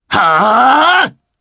Theo-Vox_Attack4_jp.wav